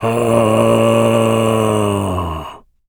Male_Long_Moan_01.wav